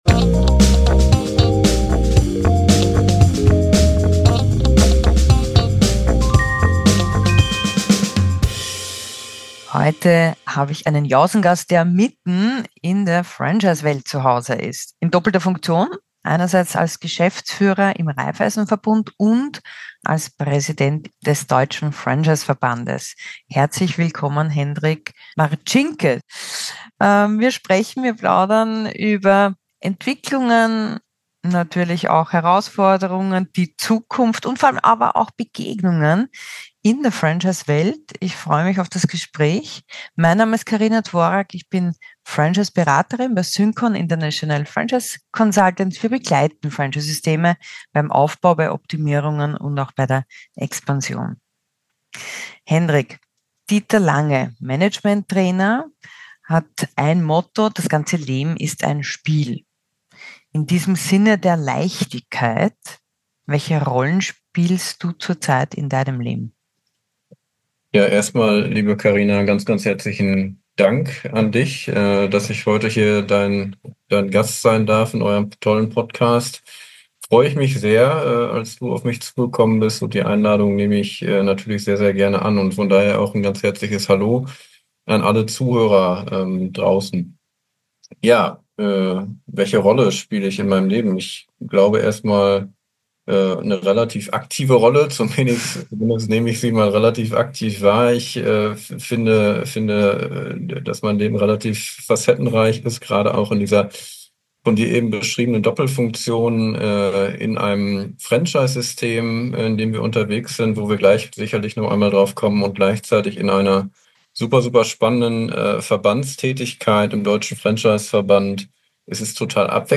Ein Gespräch über Allianzen, Finanzierungen, Franchise-Erfolgsfaktoren, Offenheit und Stammtische